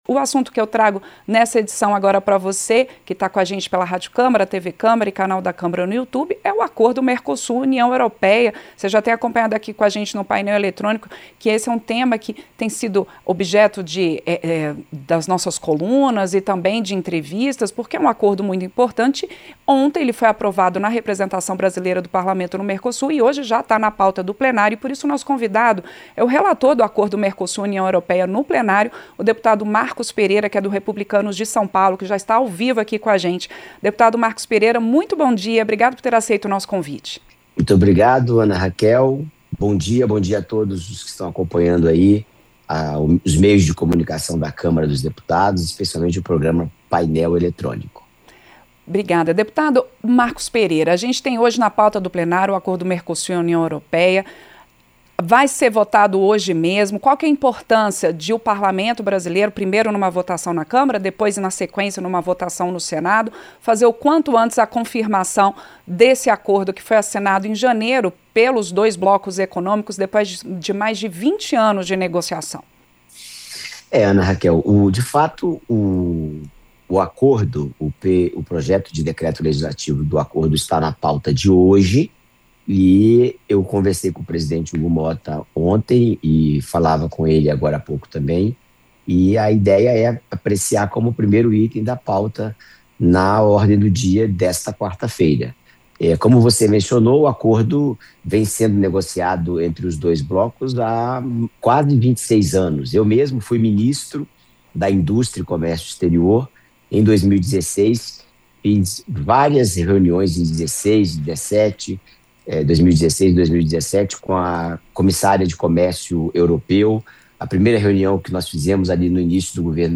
Entrevista – Dep. Marcos Pereira (Rep-SP)